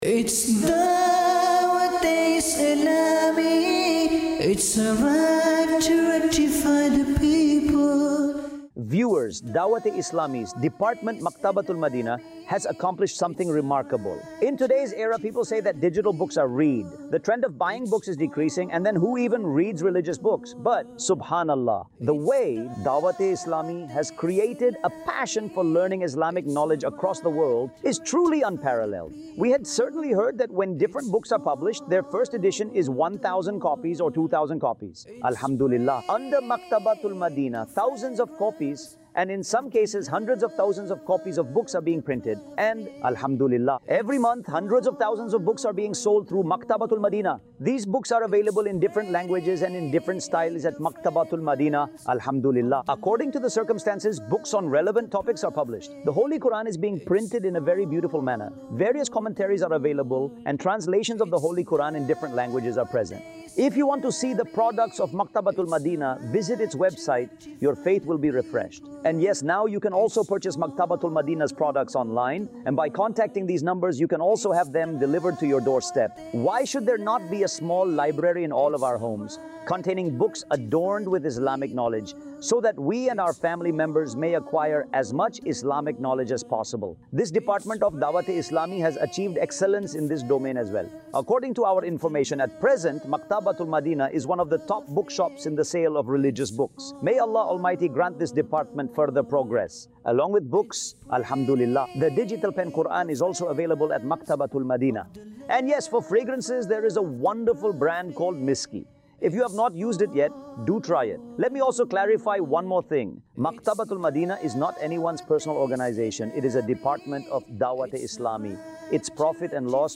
khutba
Maktaba-Tul-Madinah | Department of Dawateislami | Documentary 2026 | AI Generated Audio